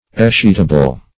Escheatable \Es*cheat"a*ble\